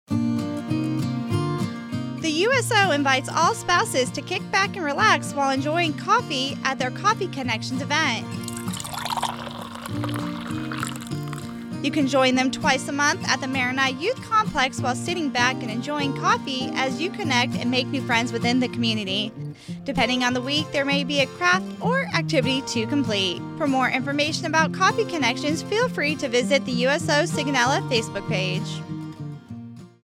NAVAL AIR STATION SIGONELLA, Italy (June 29, 2023) A radio spot describing USO Sigonella's Coffee Connections event.